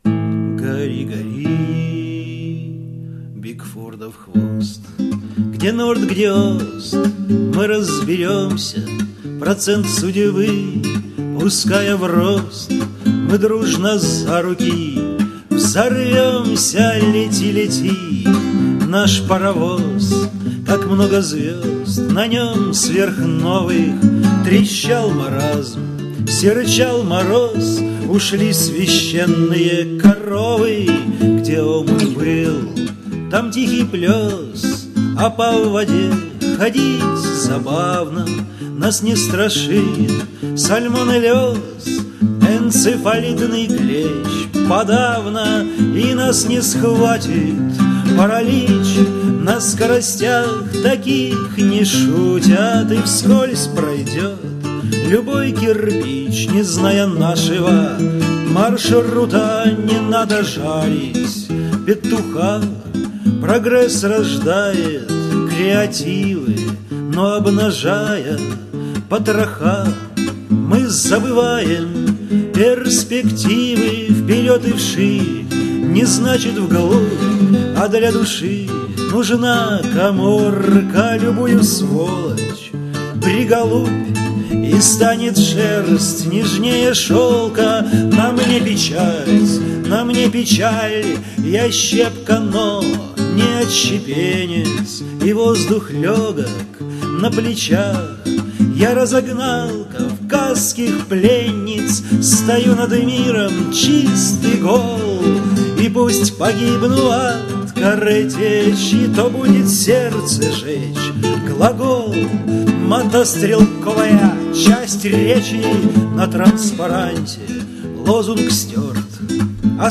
• Жанр: Авторская песня